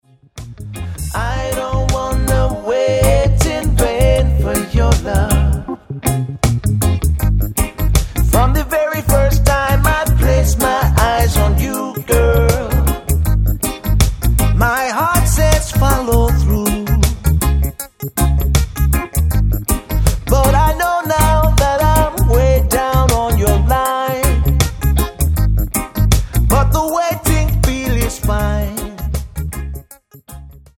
Tonart:Ab Multifile (kein Sofortdownload.
Die besten Playbacks Instrumentals und Karaoke Versionen .